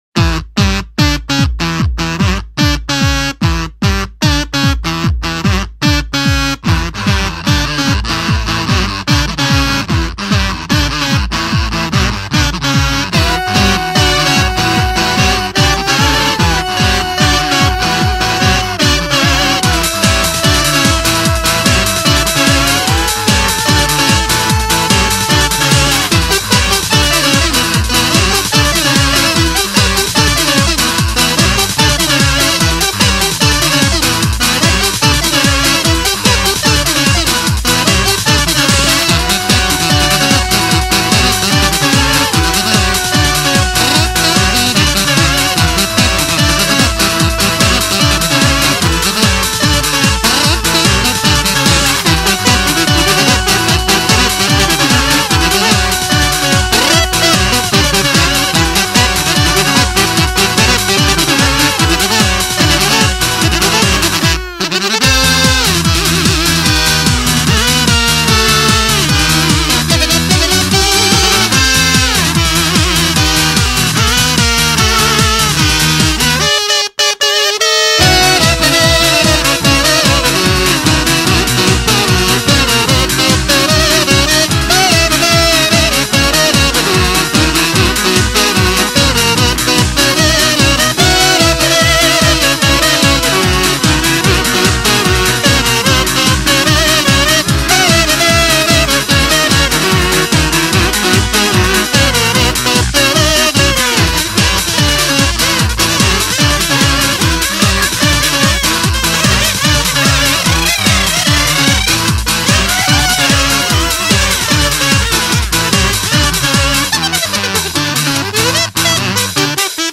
BPM148
Audio QualityPerfect (High Quality)
but the song is repetitive at points